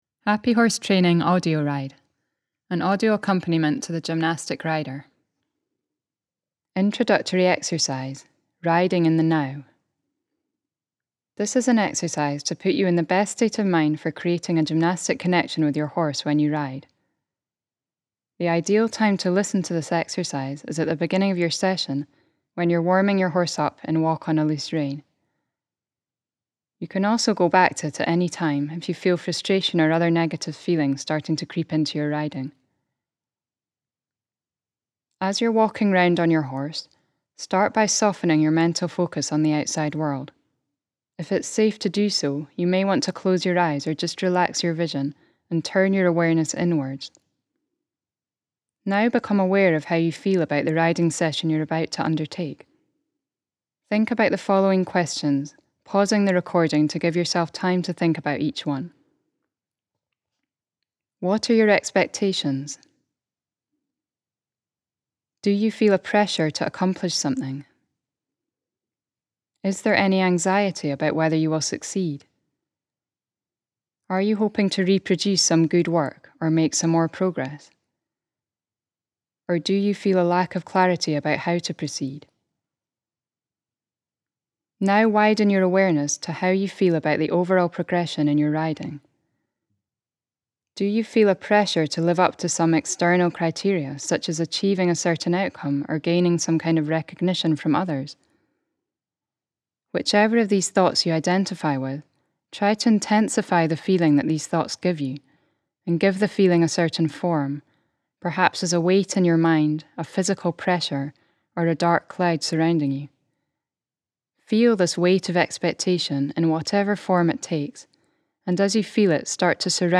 Audio descriptions guide you through each step of developing a balanced, dynamic connection with the horse through your position.
This truly innovative learning tool gives you a whole new way of being guided in your riding, in a calm, clear, step-by-step way.